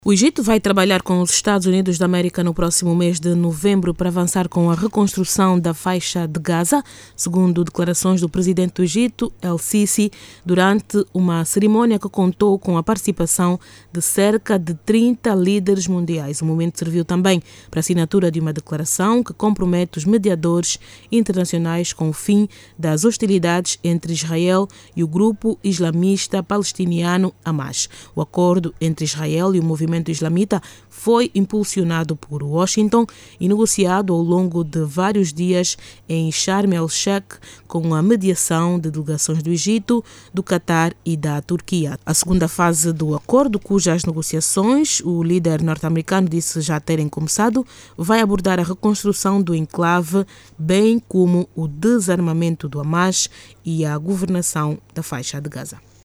O Presidente da República, João Lourenço dirige esta quarta-feira a mensagem sobre o estado da nação a propósito  da abertura do ano parlamentar.  Sobre este momento no parlamento, a RNA ouviu sindicalistas, especialistas em ciências sociais e das Engenharias sobre as suas expectativas em relação ao  discurso sobre o Estado da Nação, que além de balancear o que foi feito, vai  projectar metas para a governação nos próximos tempos.